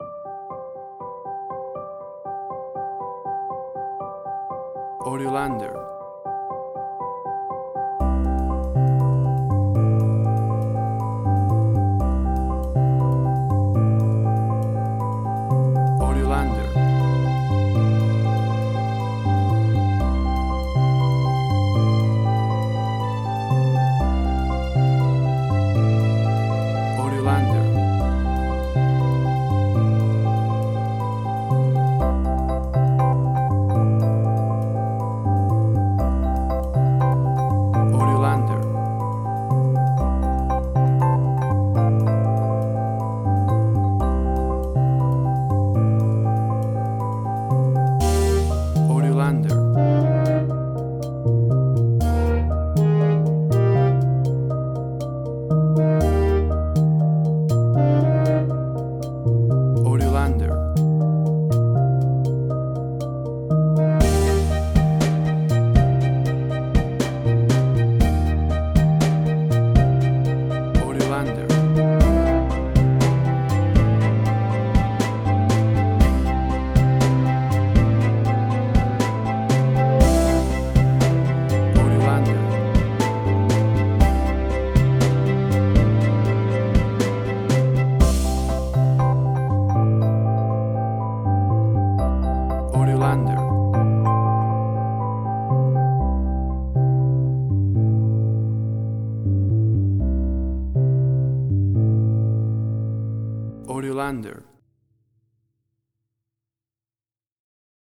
Indie Quirky.
Tempo (BPM): 120